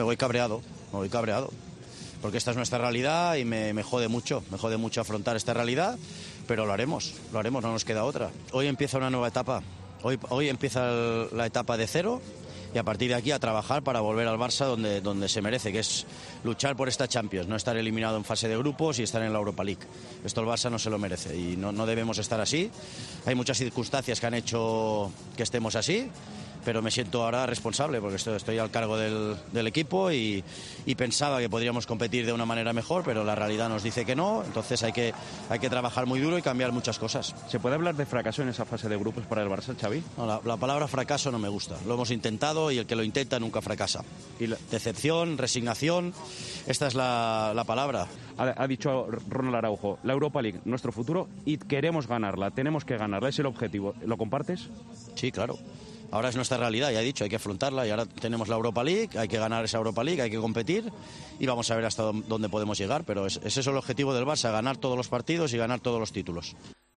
AUDIO: El entrenador del equipo azulgrana se ha mostrado muy enfadado tras el partido por la derrota y la eliminación.